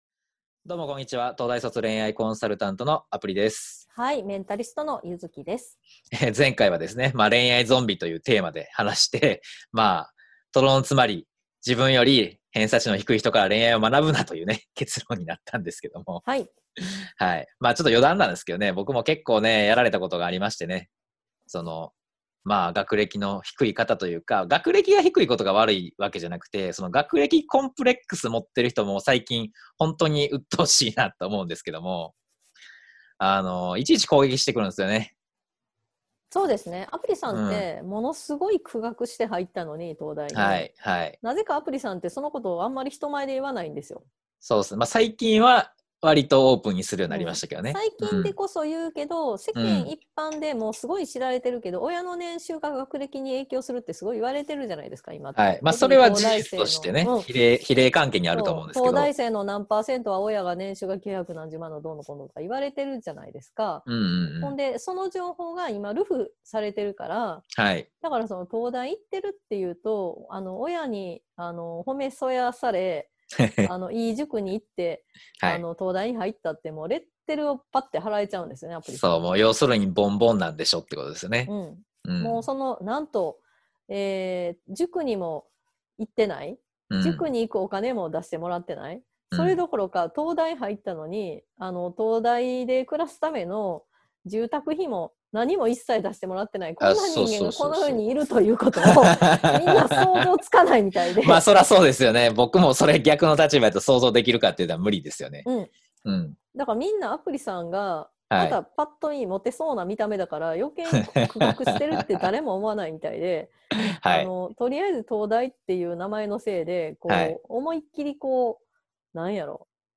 まずはこちらの対談音声をお聞きください。